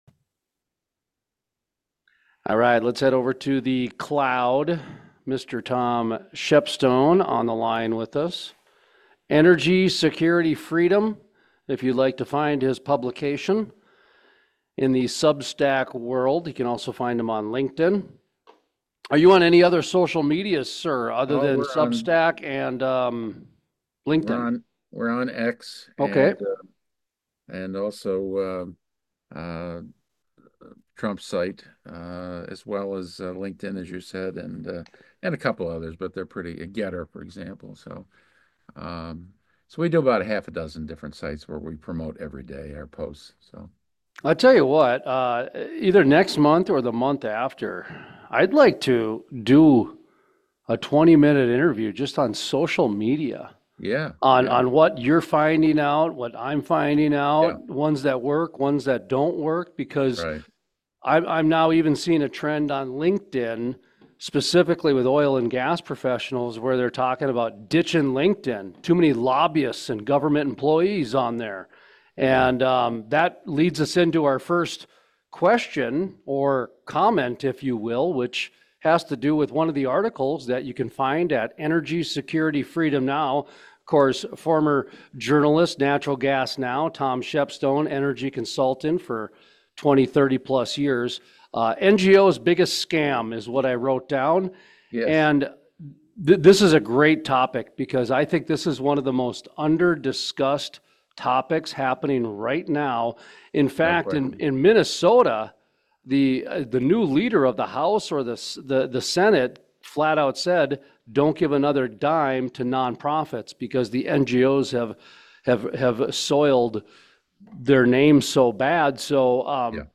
DETAILS FROM INTERVIEW STILL TO COME….